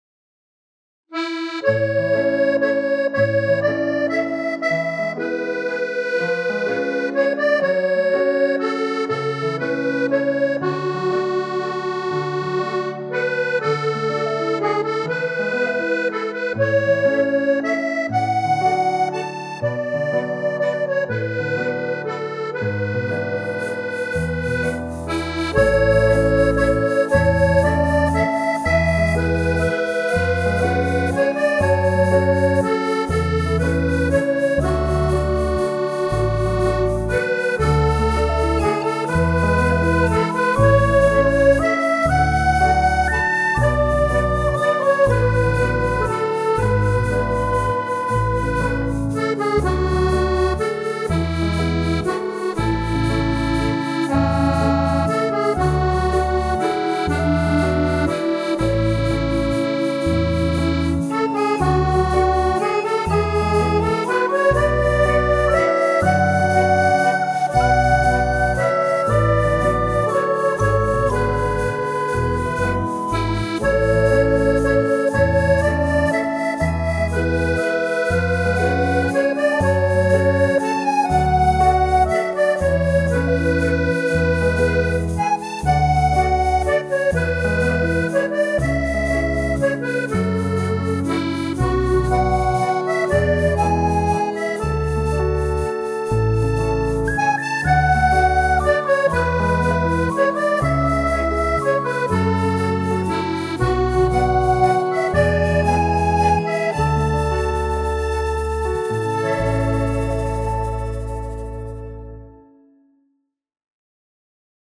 Accordion Memories
I am the one responsible for recording my part and then gathering all the pieces and mastering them to everyone’s satisfaction.